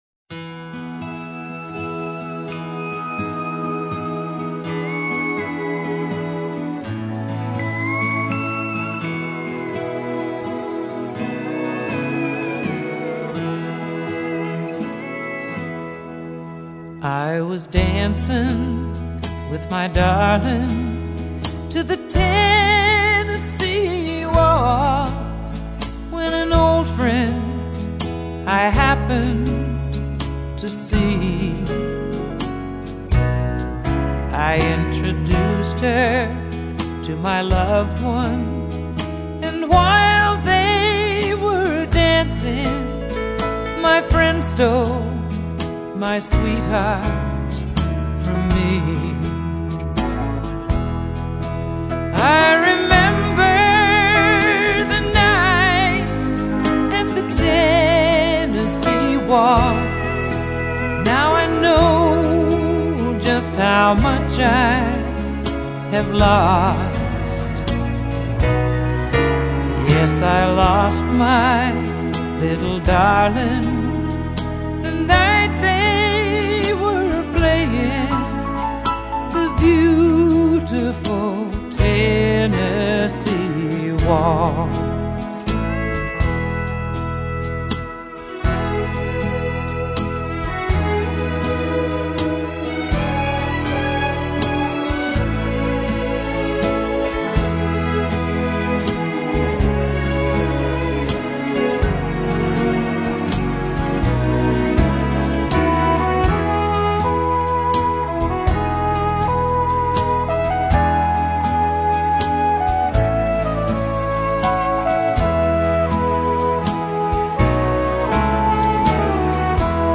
本专辑中，大部分歌曲源于40至60年代的经典佳作，以乡村曲风为主。